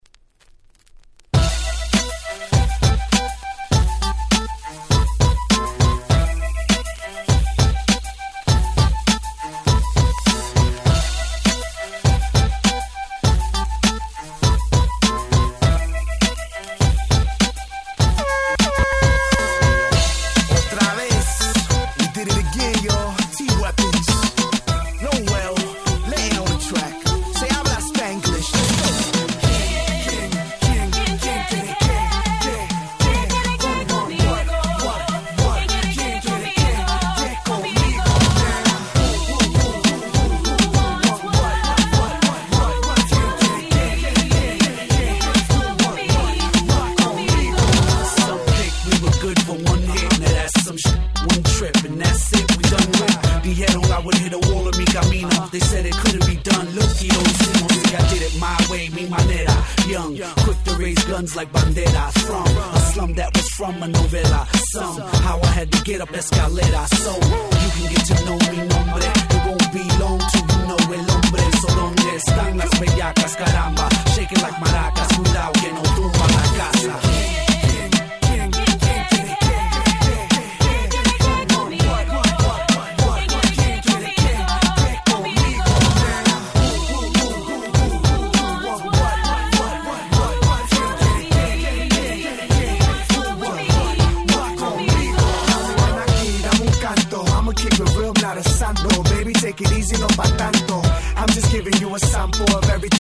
04' Smash Hit Reggaeton !!